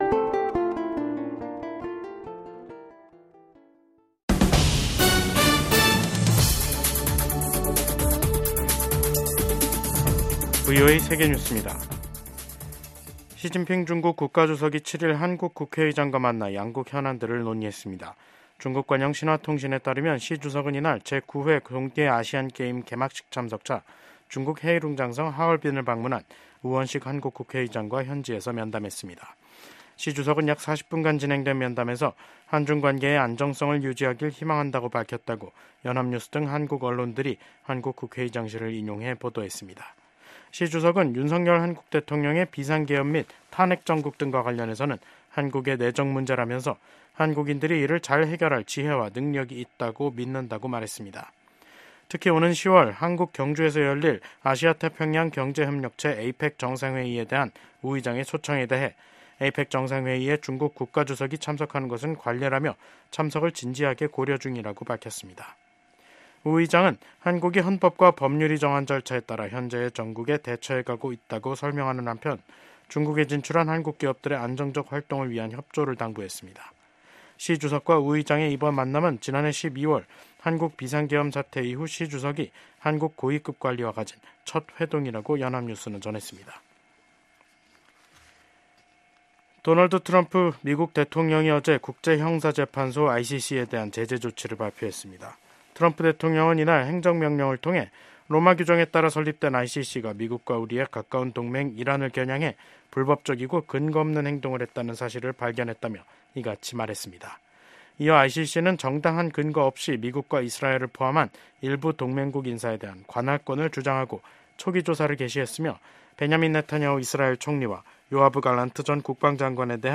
VOA 한국어 간판 뉴스 프로그램 '뉴스 투데이', 2025년 2월 7일 2부 방송입니다. 미국 도널드 트럼프 대통령의 측근인 빌 해거티 상원의원이 미한일 경제 관계는 3국 협력을 지속시키는 기반이 될 수 있다고 강조했습니다. 한국에서 정부는 물론 방산업체 등 민간기업들까지 중국의 생성형 인공지능(AI) 딥시크 접속 차단이 확대되고 있습니다.